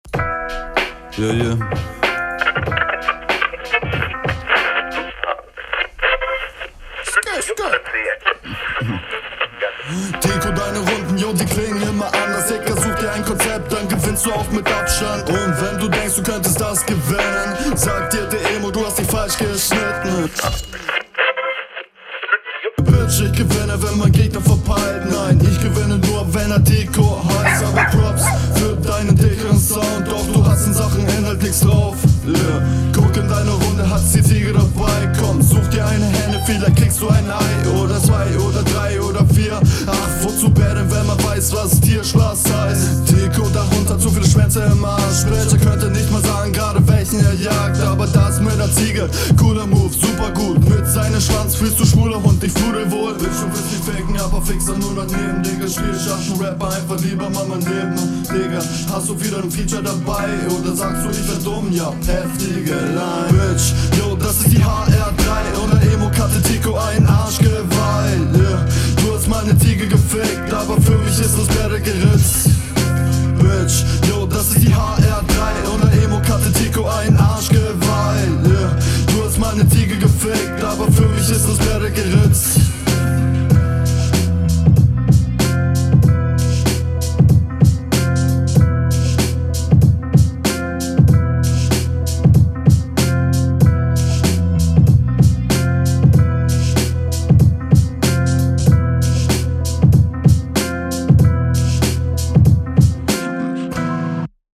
Hmmm der Beat passt nicht ganz dir vom Tempo.
du passt hier wieder besser auf den beat als hr1. klingt noch immer nicht richtig …